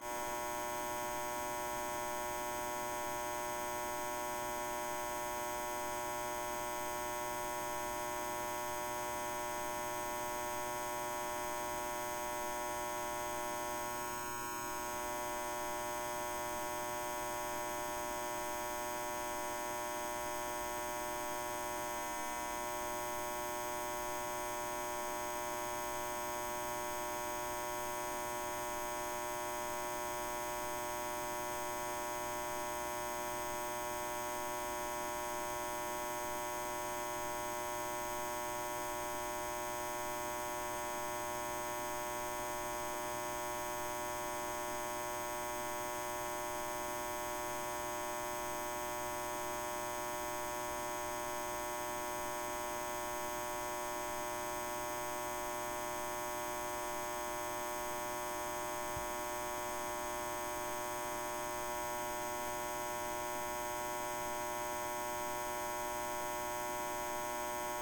Electric Hum.mp3